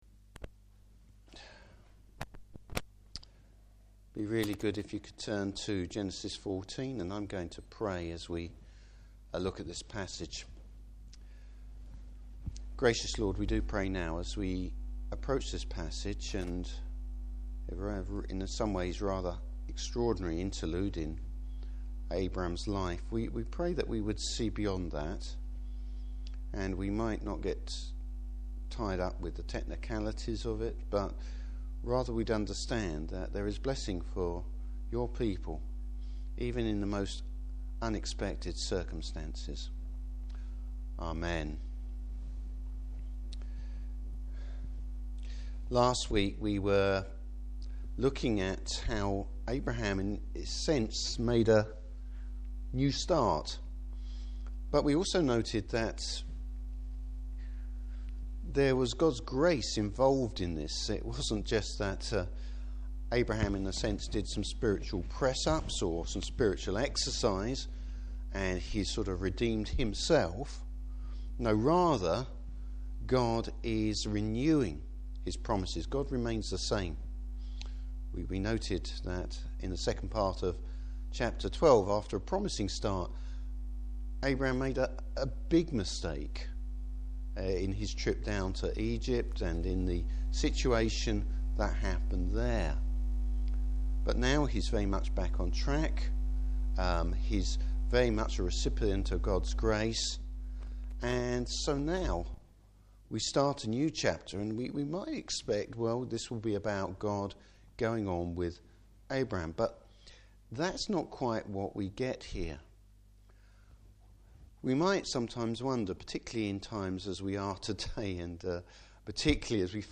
Service Type: Evening Service Blessing from an unexpected source at an unexpected time!